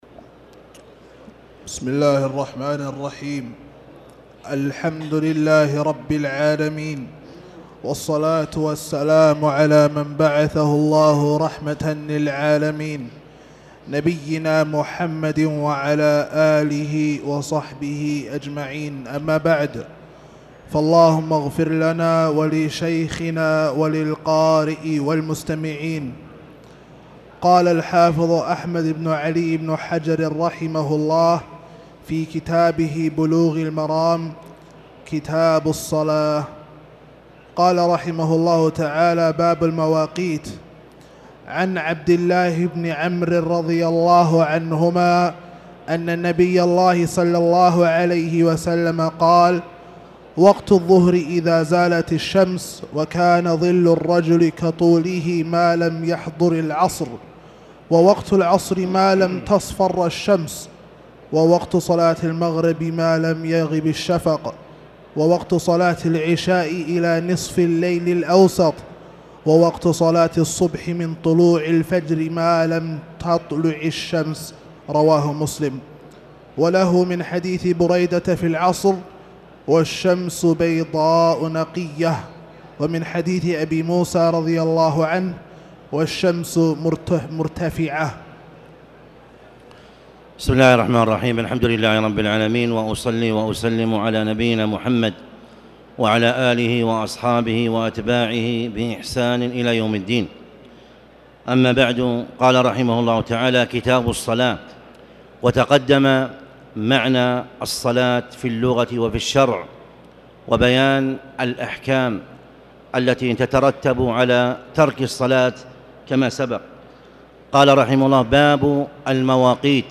تاريخ النشر ٢٦ جمادى الأولى ١٤٣٨ هـ المكان: المسجد الحرام الشيخ